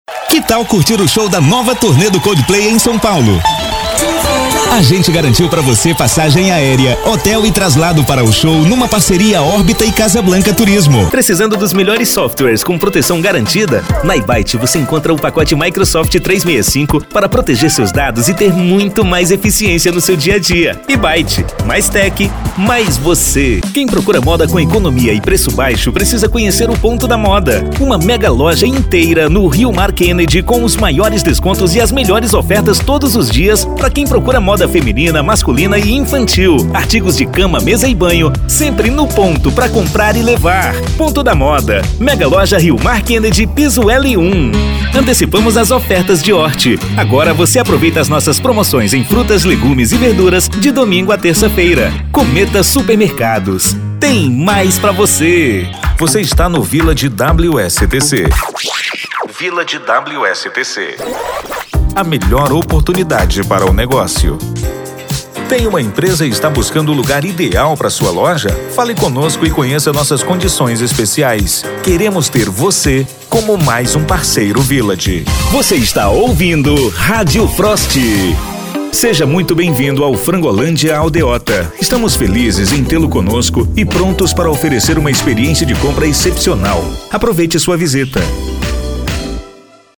Spot Comercial
Vinhetas
Animada